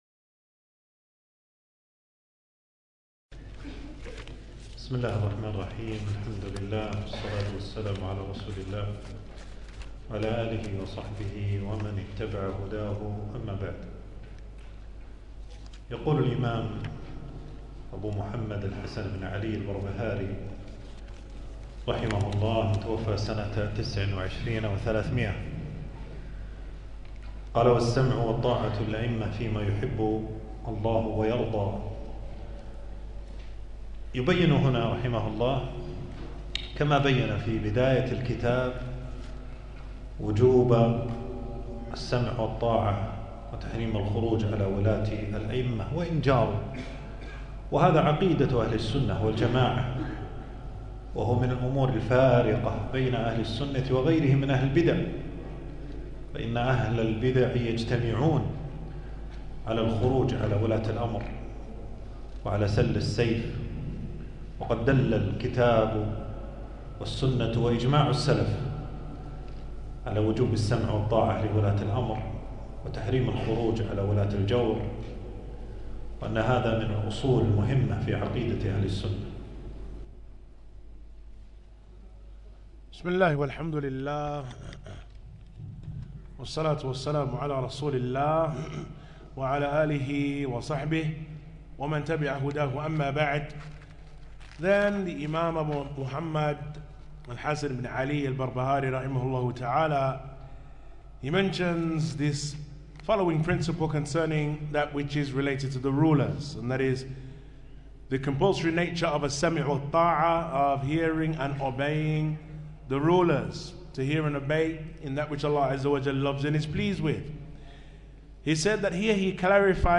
تنزيل تنزيل التفريغ العنوان: شرح، شرح السنة للبربهاري. (الدرس الثاني) ألقاه
المكان: درس ألقاه يوم السبت 17جمادى الأول 1447هـ في مسجد السعيدي.